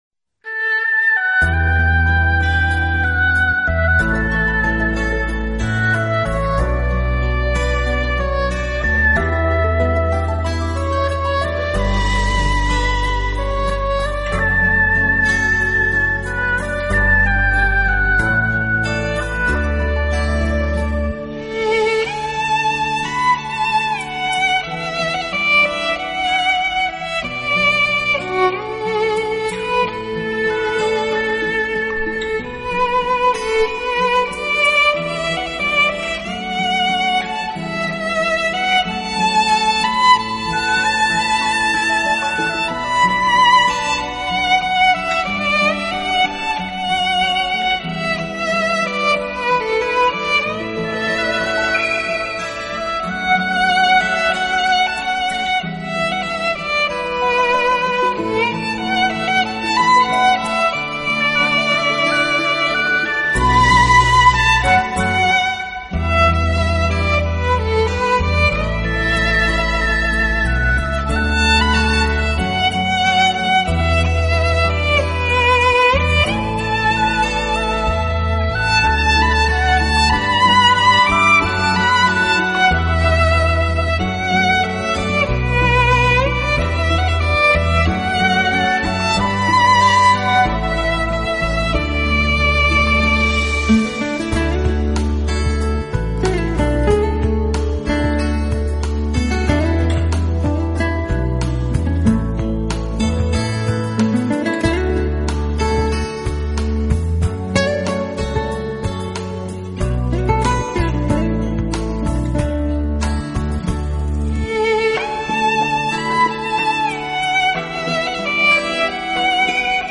音乐类型：pop